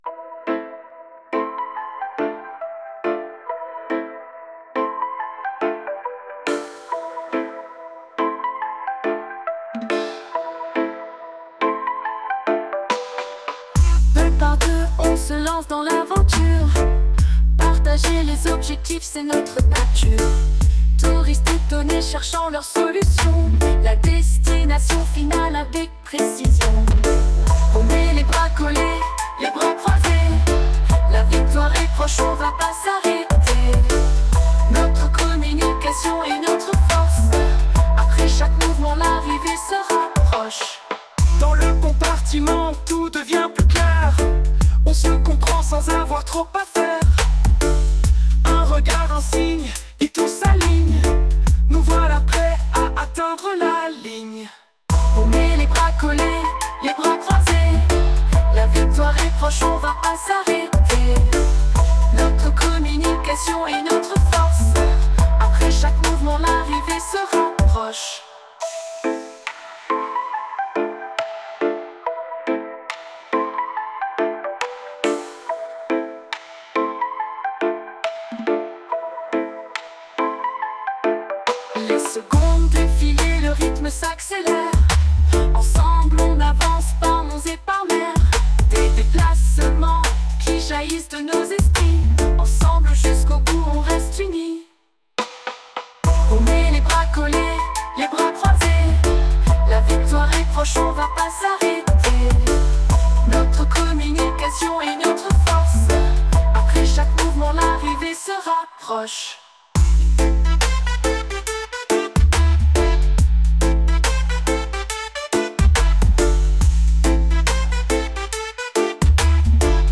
La suivante, c'est le classique jeu, reggae et �le ...